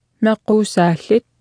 Martha tassaavoq kalaallisut qarasaasiakkut atuffassissut.